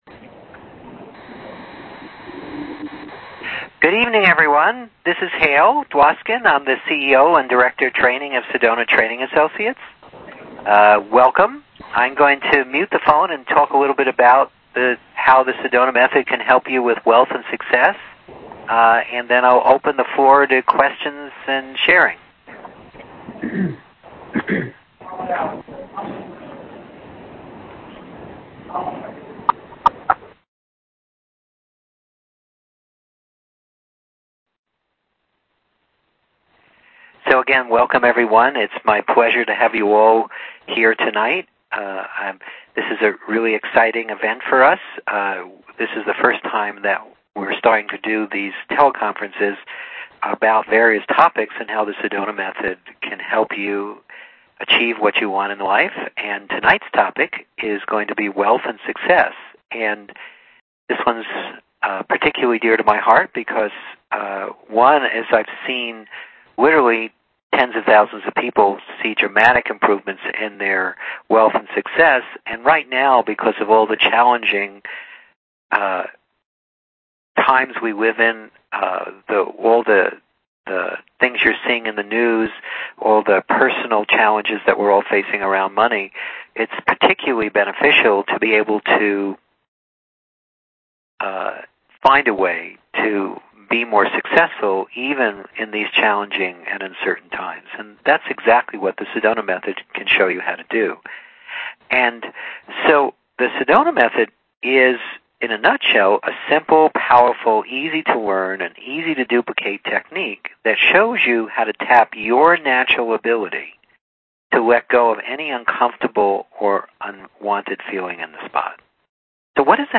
Here is the link to the recorded teleconference.